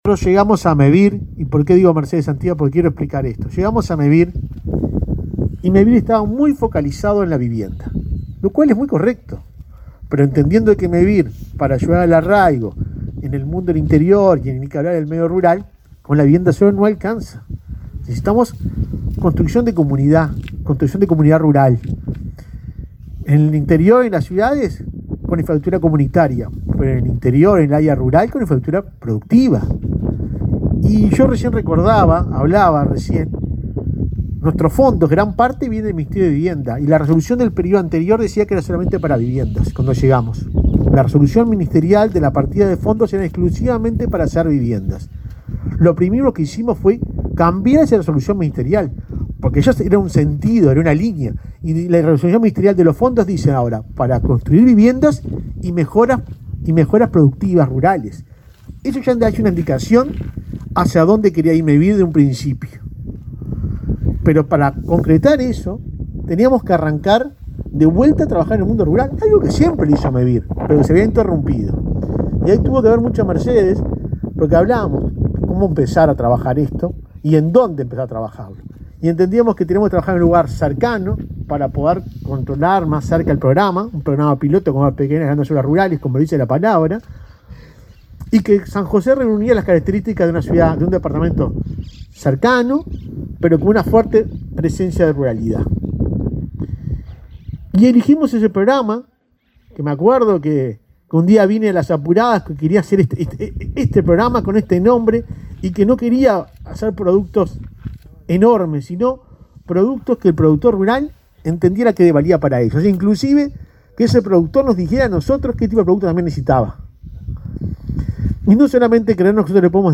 Palabras de autoridades en inauguración de Mevir
El presidente de la entidad, Juan Pablo Delgado; el ministro de Ganadería, Fernando Mattos, y su par de Vivienda, Raúl Lozano, destacaron la importancia de las obras.